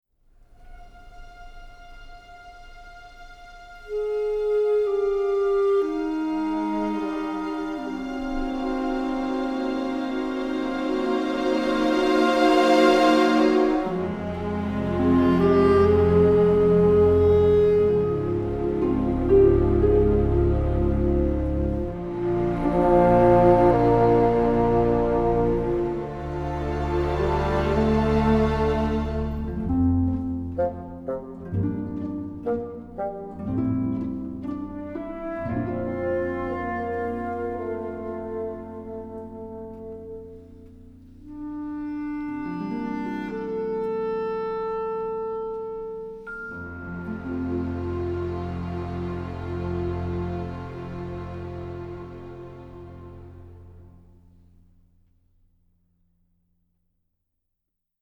سبک موسیقی (Genre) موسیقی متن